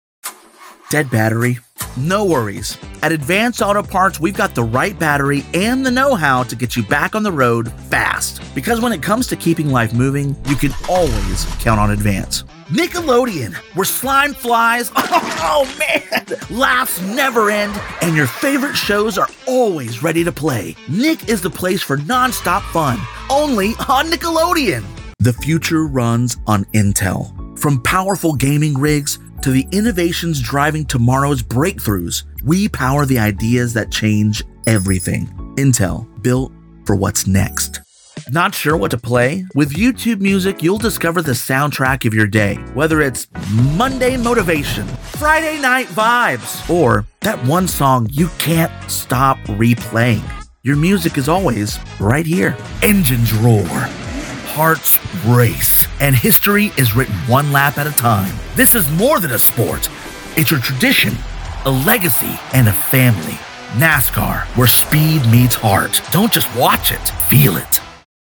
Commercial Demo 2025
English - USA and Canada
English - Southern U.S. English
English - British RP
Young Adult
Middle Aged